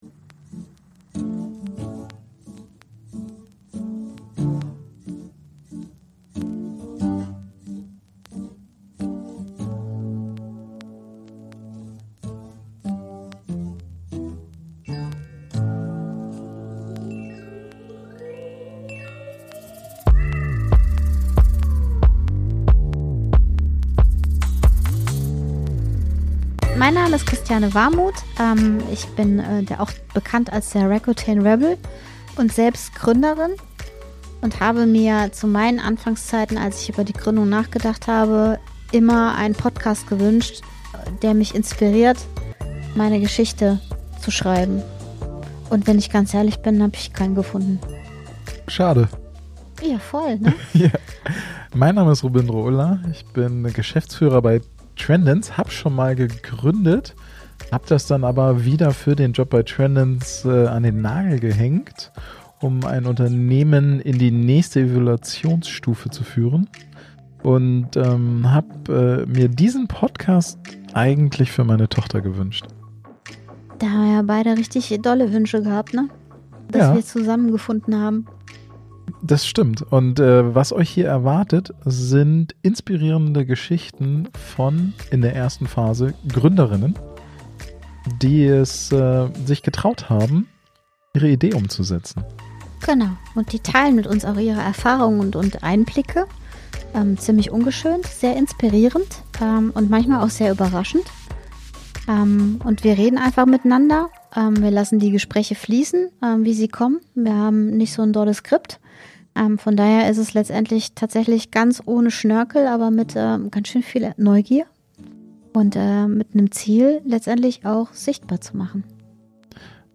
Wo zwei Stimmen auf Geschichten